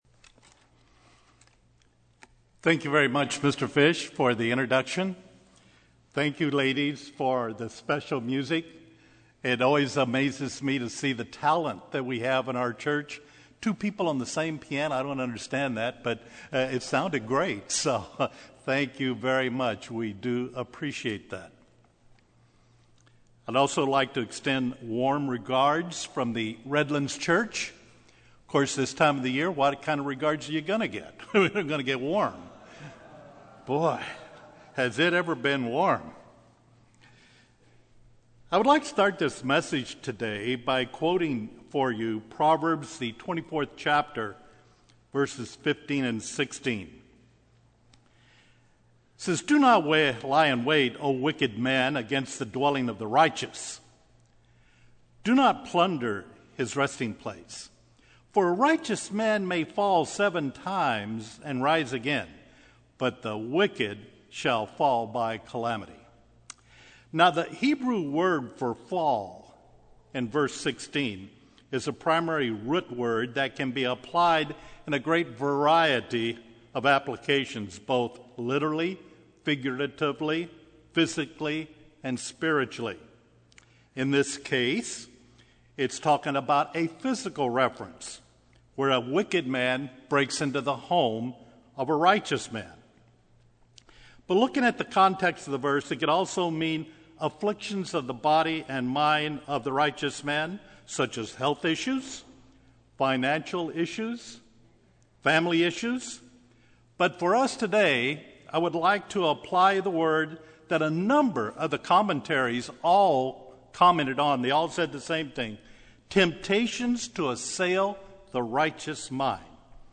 Given in Los Angeles, CA